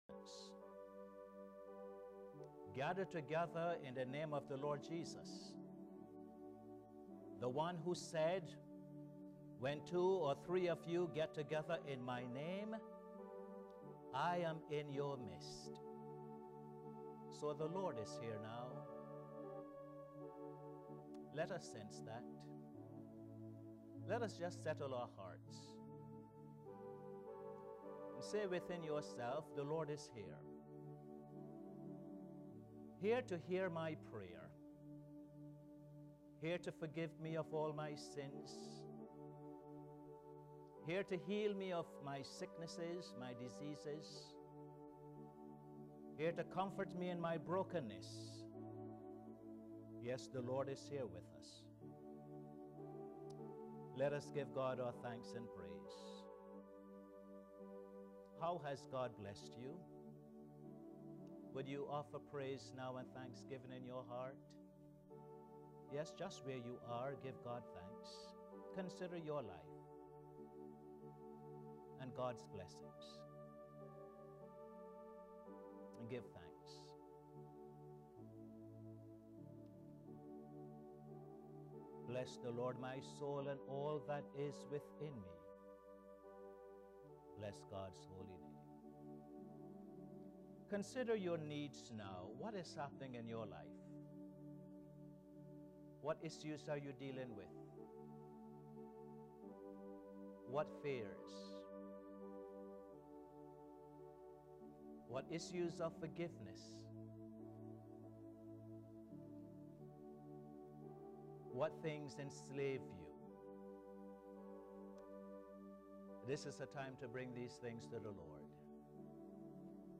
Posted in Sermons on 24.